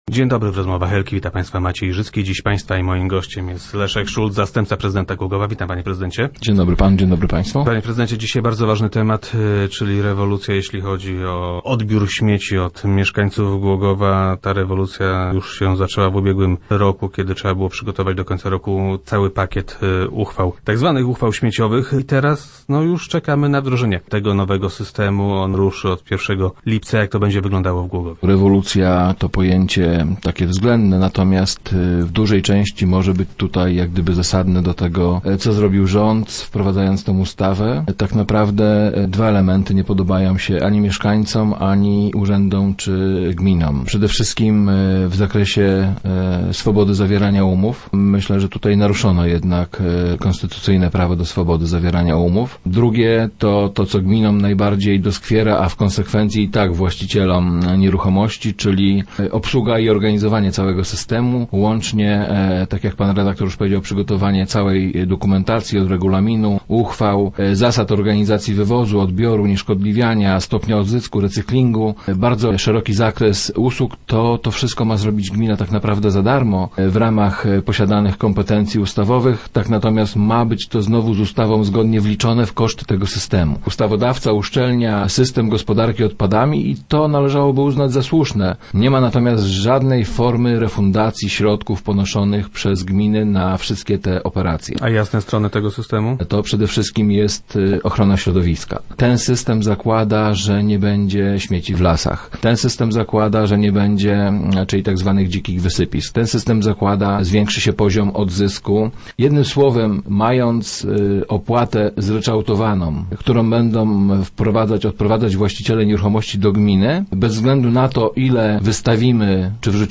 Większość obowiązków spadnie na samorządy. - Ta ustawa ma jednak także swoje ciemne strony – twierdzi Leszek Szulc, zastępca prezydenta Głogowa, który był gościem Rozmów Elki.